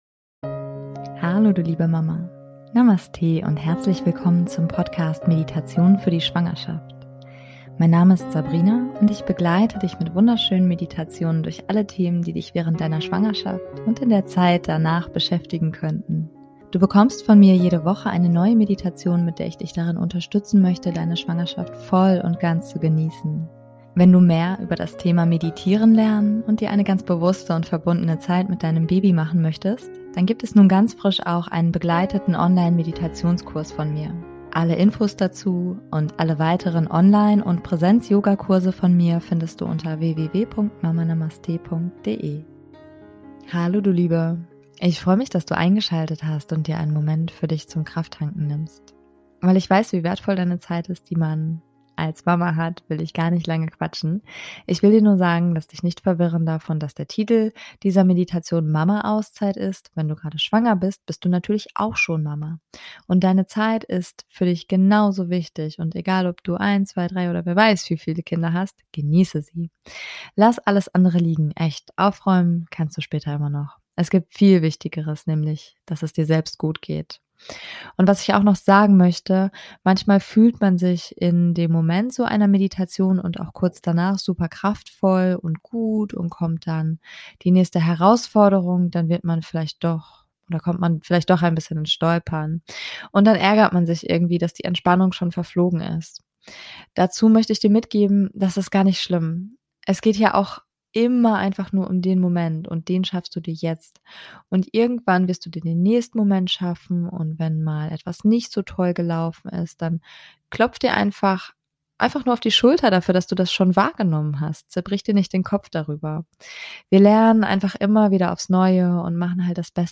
#095 - Mama Auszeit - Meditation [Schwanger & Mama] ~ Meditationen für die Schwangerschaft und Geburt - mama.namaste Podcast